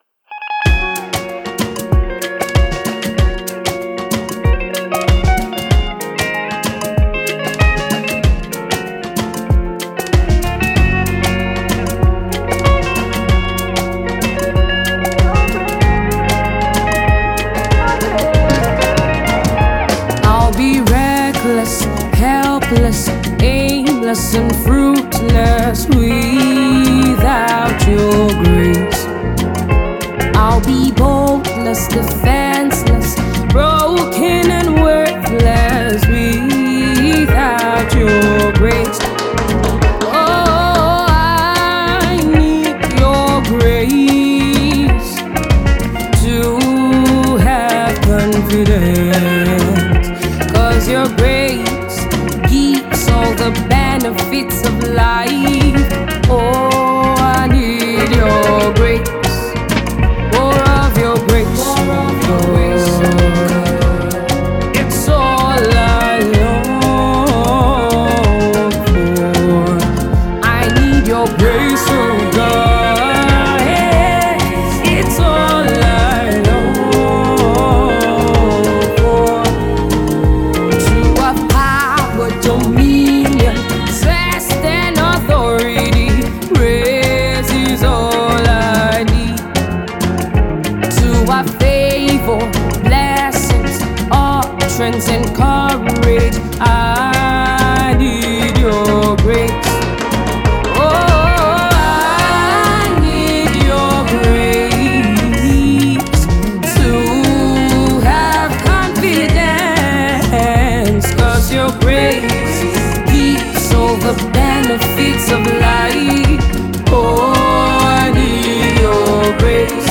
Afro Gospel song